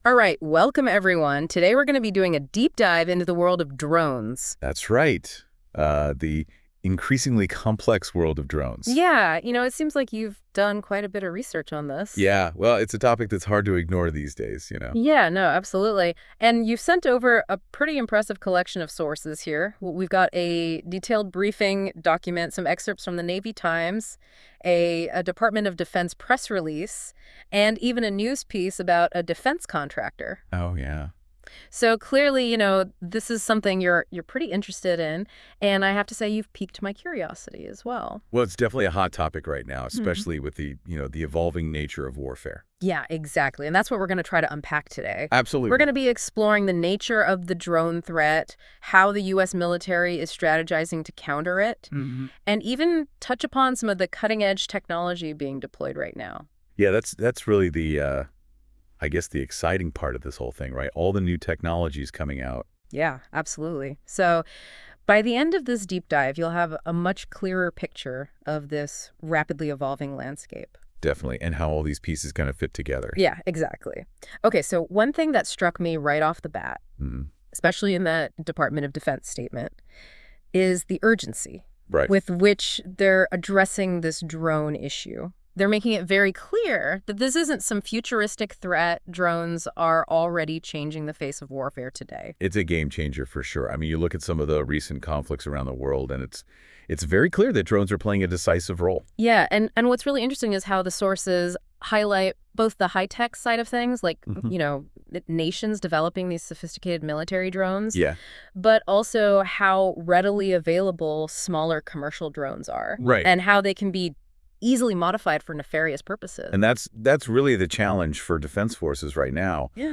Listen to a convo on it all…. much more below